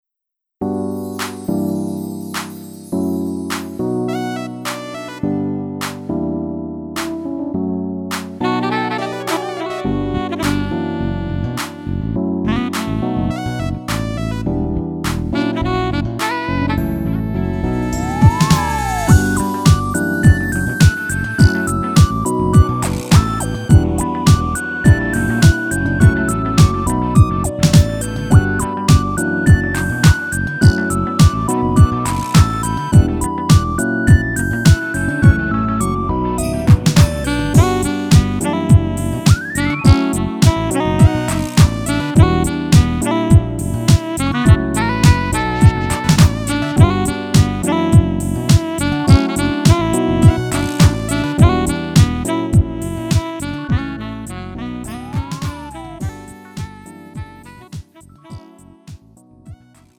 음정 -1키
장르 구분 Lite MR